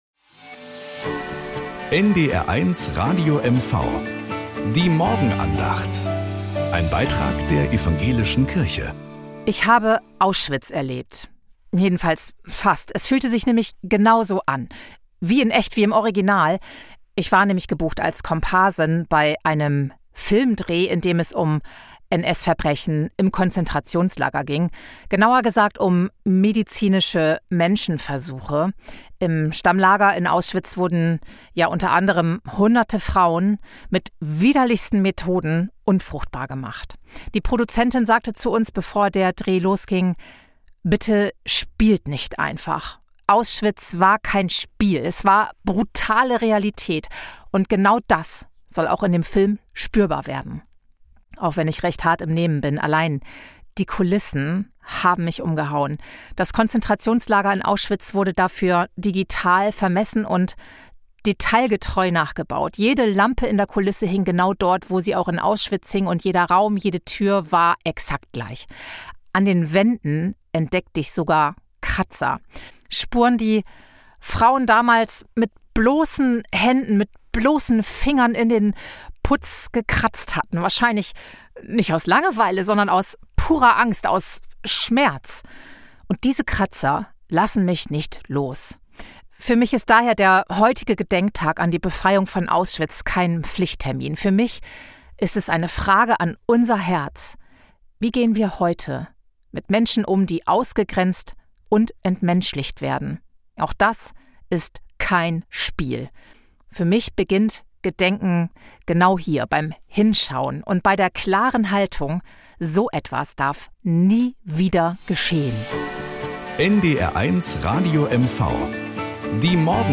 Morgenandacht bei NDR 1 Radio MV